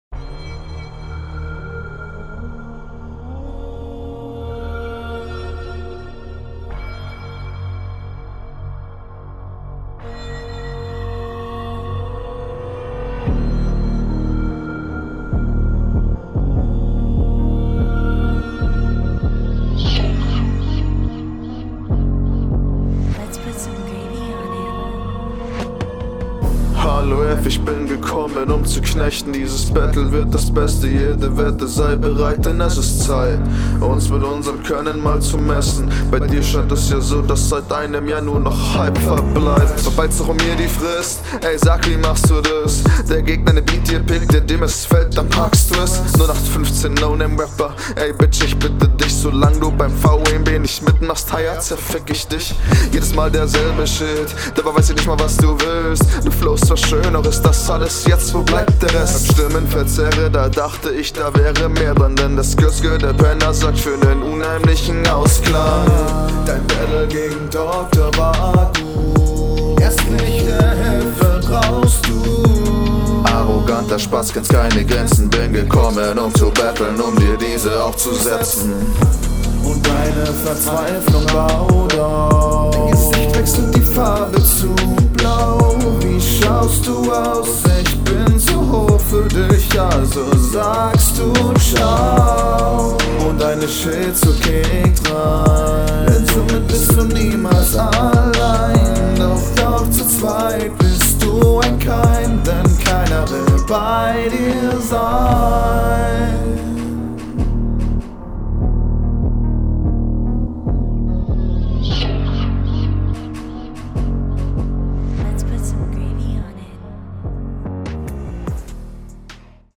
scheinst nicht so ein gutes Mic zu haben aber Mix holt da viel raus. insgesamt …